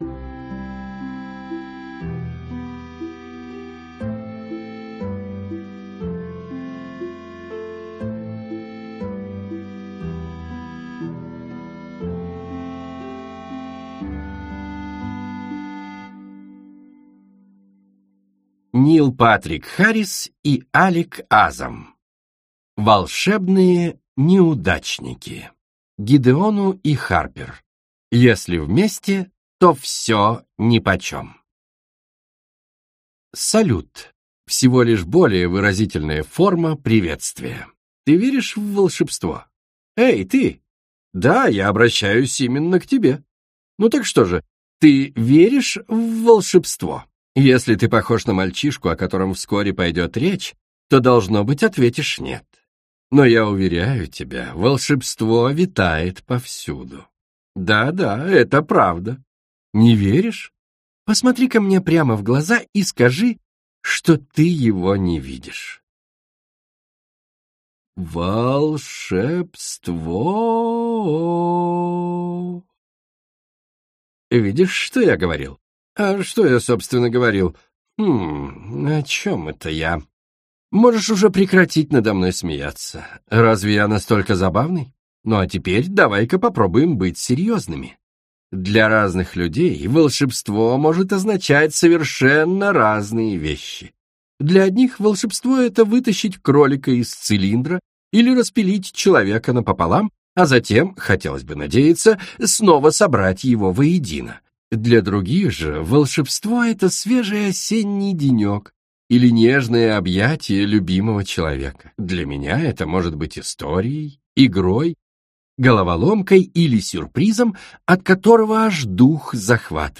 Аудиокнига Волшебные неудачники - Скачать книгу, слушать онлайн